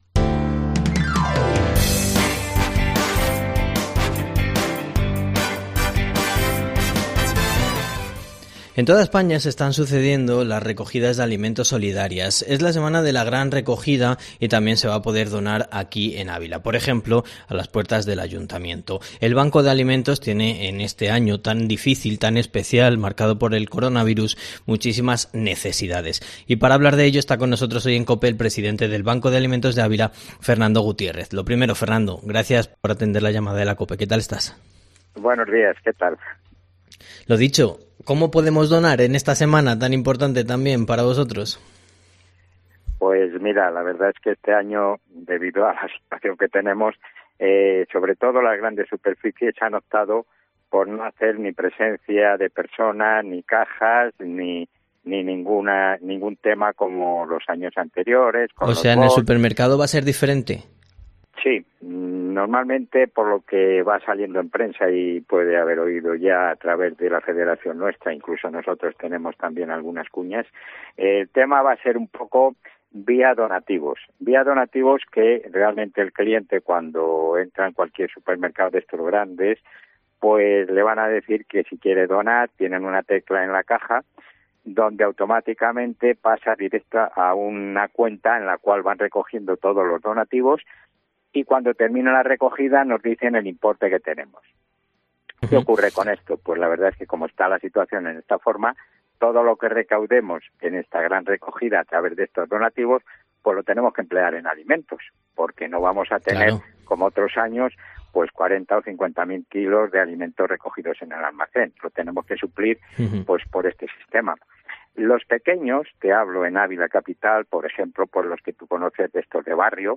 Herrera en COPE en Ávila Entrevista